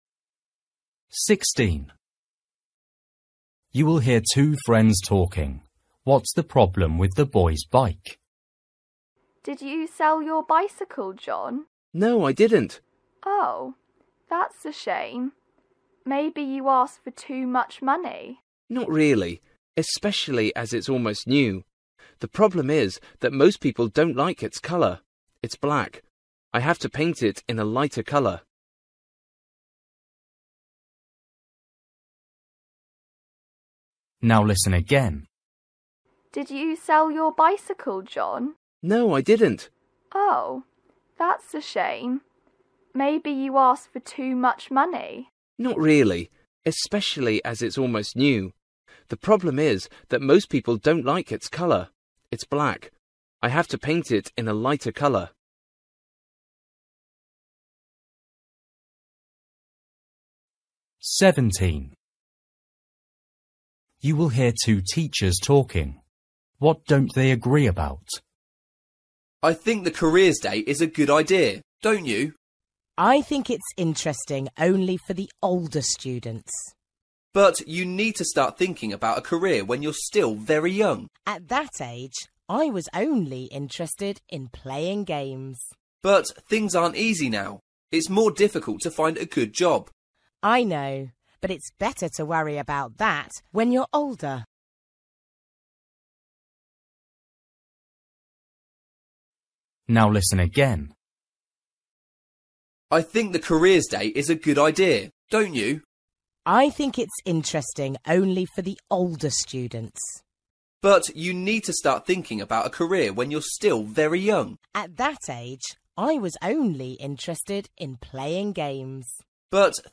Listening: everyday short conversations
16   You will hear two friends talking. What’s the problem with the boy’s bike?
17   You will hear two teachers talking. What don’t they agree about?
19   You will hear a girl talking about her holiday. What didn’t she like about her holiday?